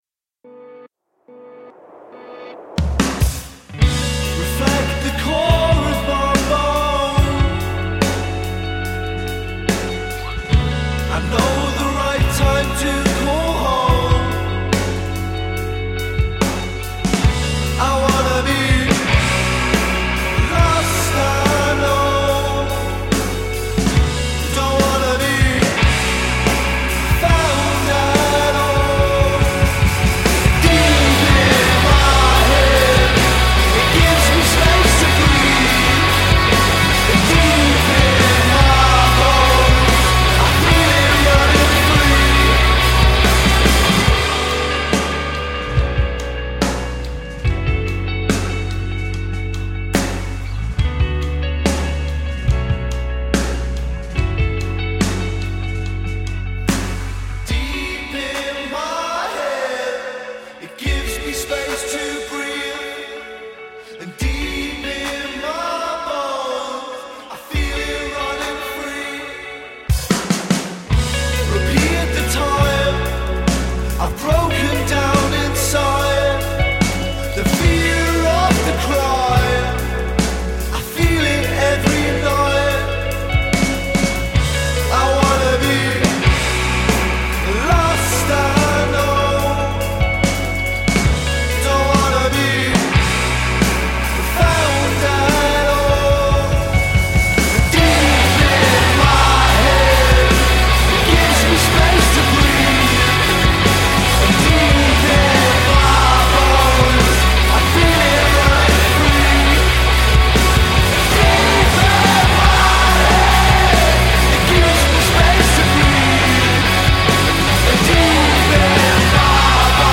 savage, powerful statement track
Reverb soaked guitars create a haunting backdrop